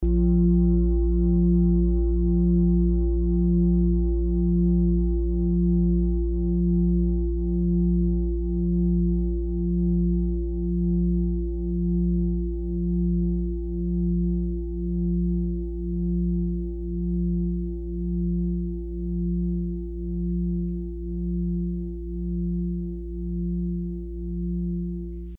Fuß-Klangschale Nr.9
Klangschale-Durchmesser: 46,6cm
Diese große Klangschale wurde in Handarbeit von mehreren Schmieden im Himalaya hergestellt.
(Ermittelt mit dem Gummischlegel)
fuss-klangschale-9.mp3